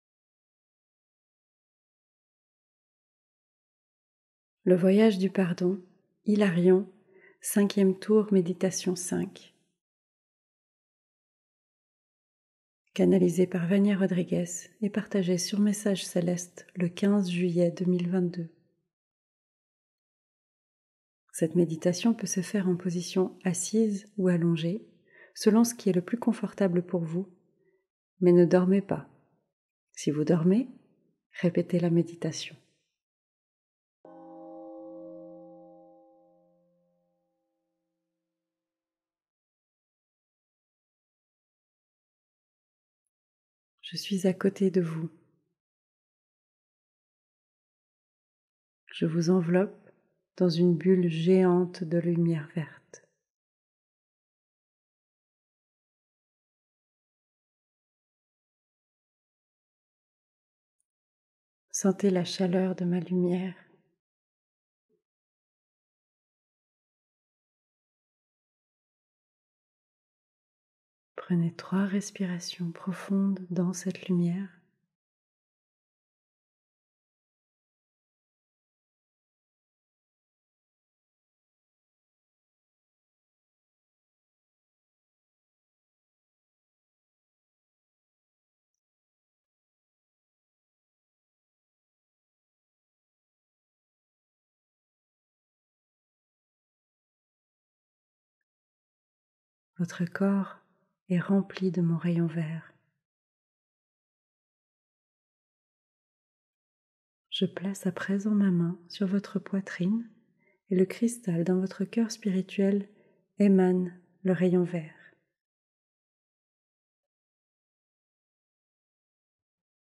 Tour 5 - Méditation 5 - sans_pub